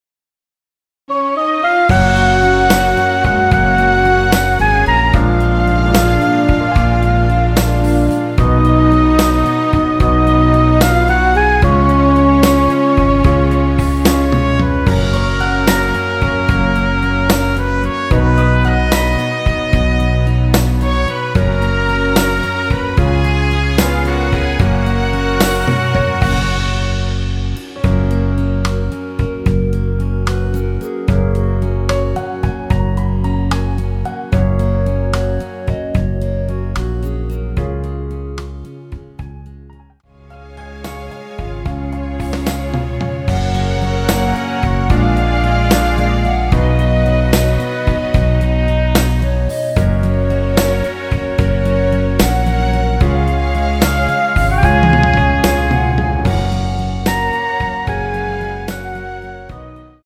원키 멜로디 포함된 MR입니다.
F#
앞부분30초, 뒷부분30초씩 편집해서 올려 드리고 있습니다.
중간에 음이 끈어지고 다시 나오는 이유는